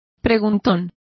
Complete with pronunciation of the translation of inquisitive.